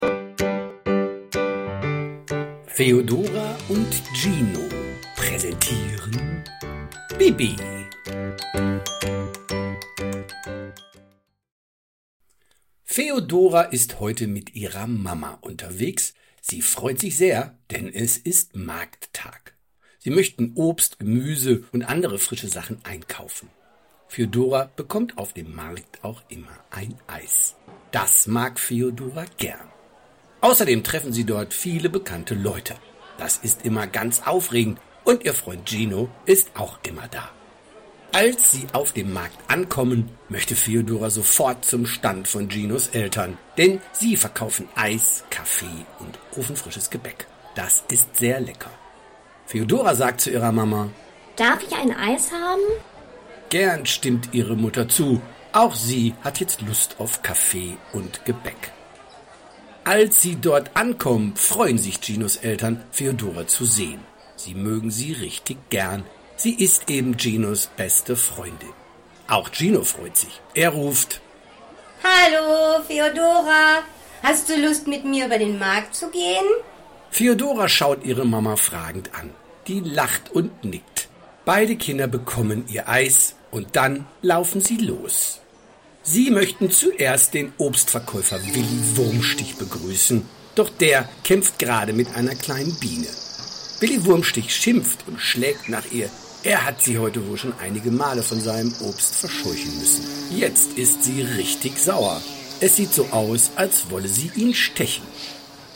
Bibbi – Das Hörspiel zum beliebten Bilderbuch!
Eine liebevoll gesprochene Version voller Wärme und Abenteuer, ideal zum Einschlafen, Entspannen oder einfach zum Zuhören.